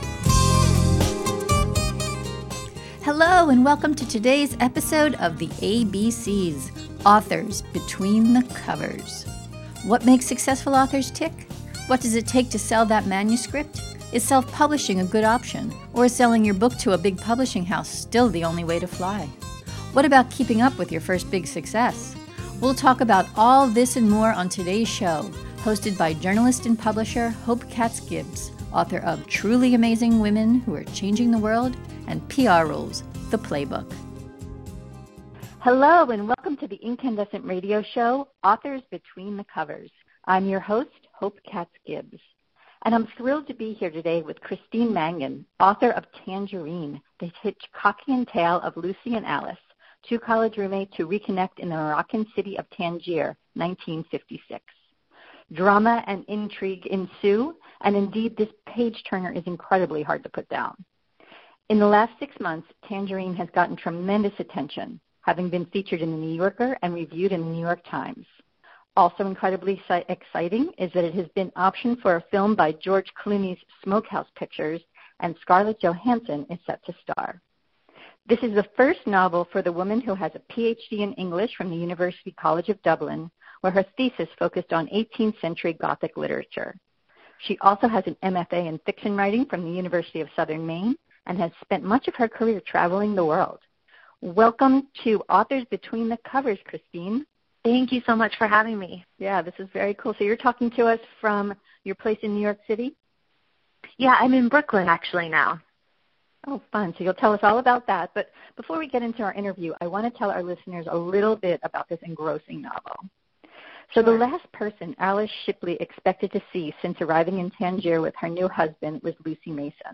Before we get into our interview, I want to tell our listeners a little about this engrossing novel: The last person Alice Shipley expected to see since arriving in Tangier with her new husband was Lucy Mason.